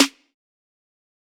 TC SNARE 25.wav